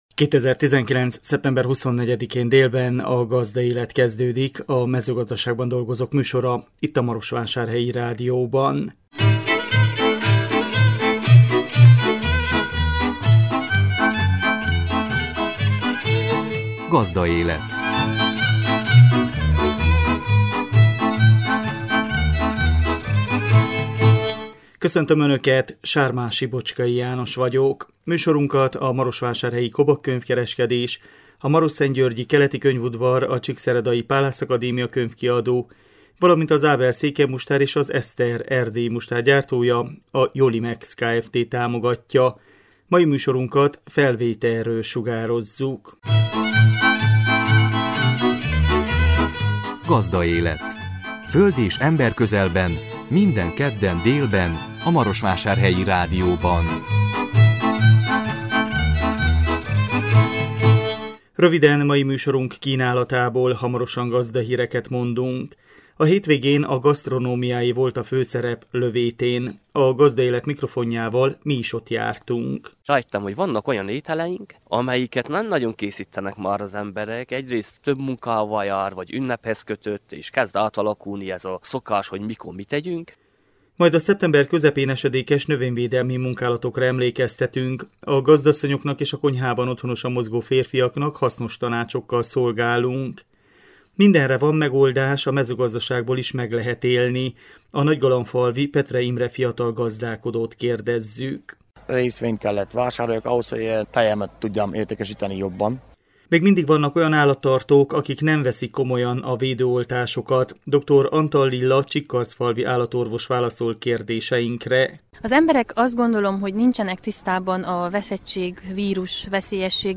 A 2019 szeptember 24-én jelentkező műsor tartalma: Gazdahírek, A hétvégén a gasztronomiáé volt a főszerep Lövétén. A Gazdaélet mikrofonjával mi is ott jártunk. Majd a szeptember közepén esedékes növényvédelmi munkálatokra emlékeztetünk.